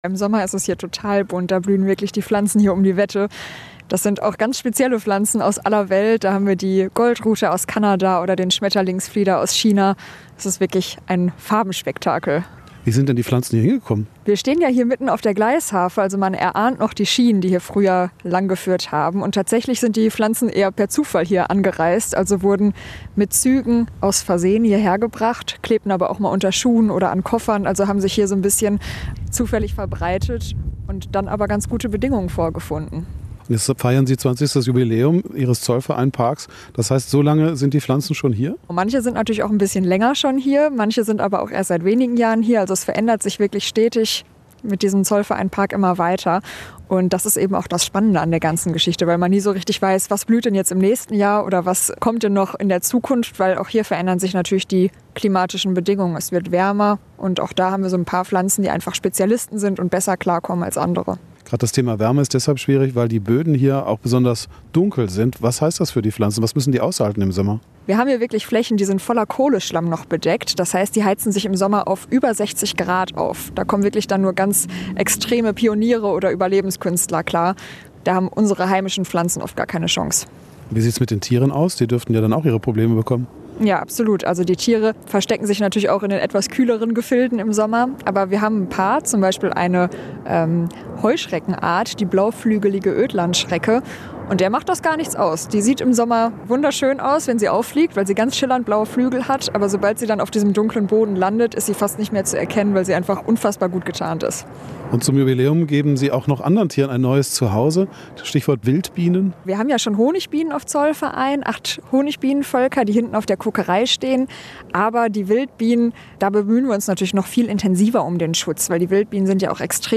Unterwegs in der Natur auf Zollverein